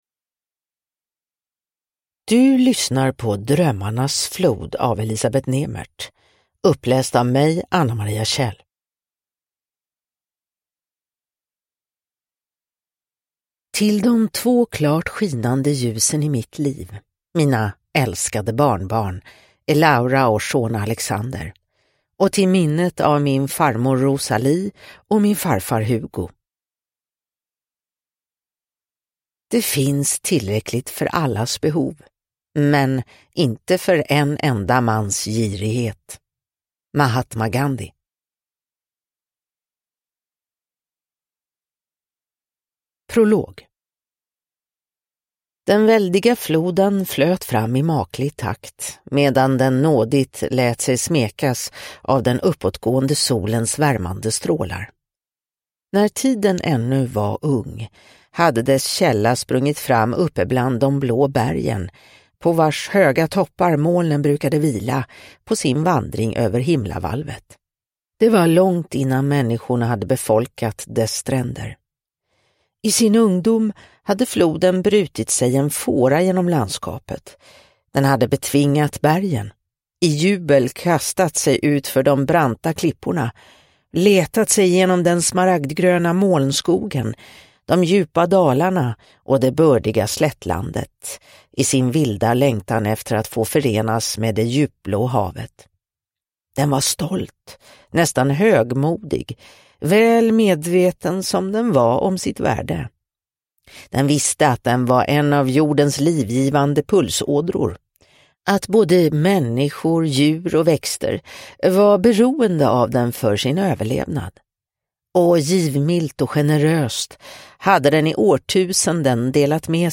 Drömmarnas flod – Ljudbok – Laddas ner